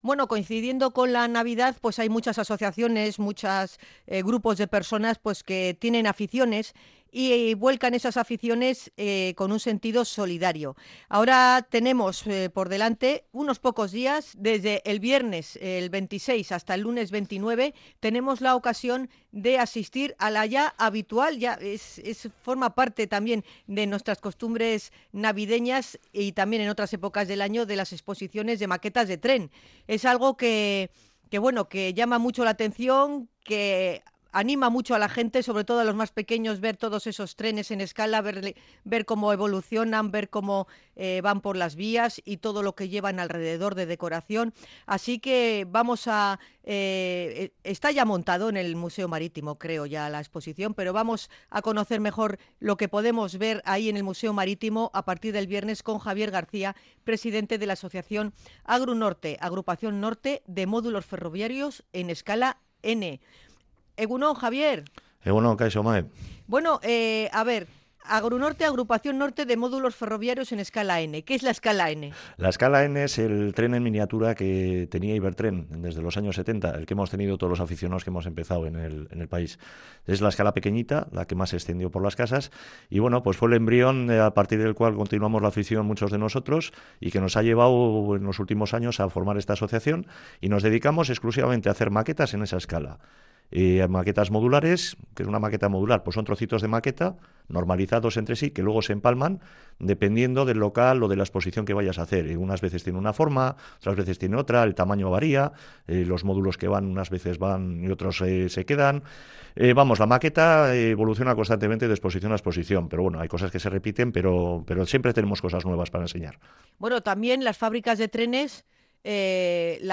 INT.-EXPO-TRENES-2025.mp3